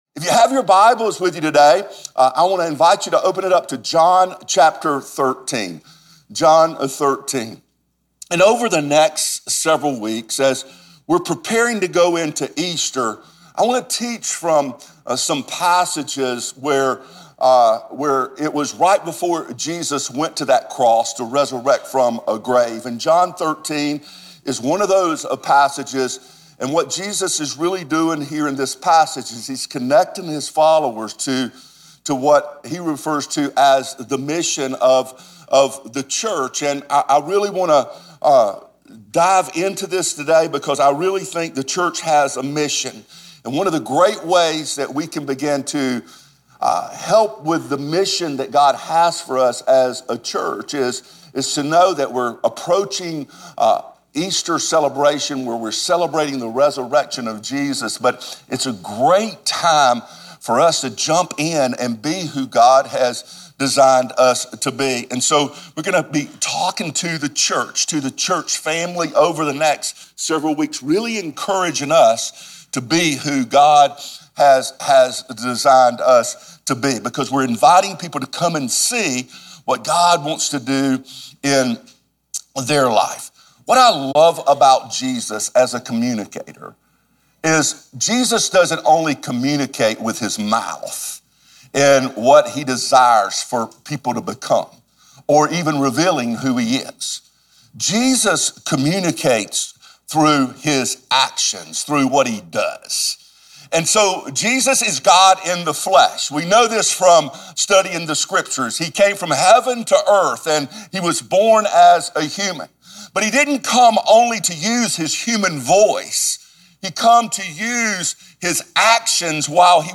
a dynamic, high-energy speaker with a heart and vision to reach the world